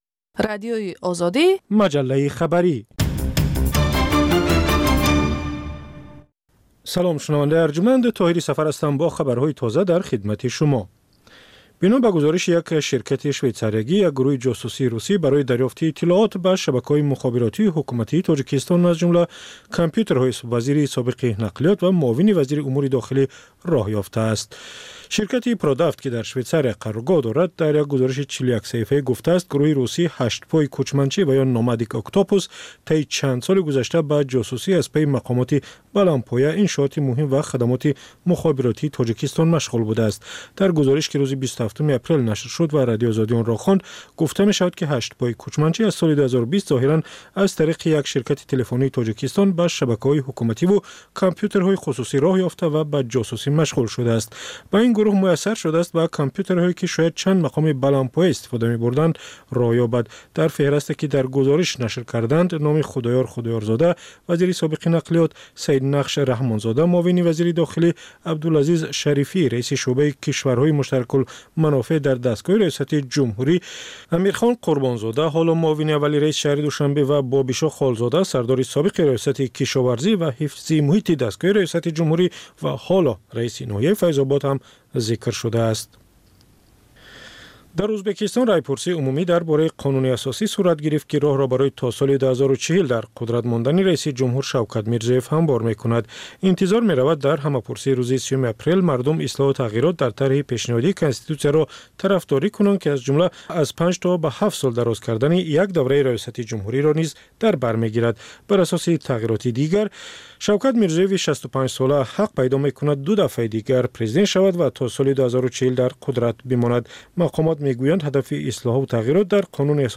Тозатарин ахбори ҷаҳон, минтақа ва Тоҷикистон, таҳлилу баррасиҳо, мусоҳиба ва гузоришҳо аз масъалаҳои сиёсӣ, иҷтимоӣ, иқтисодӣ, фарҳангӣ ва зистмуҳитии Тоҷикистон.